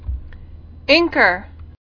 [ink·er]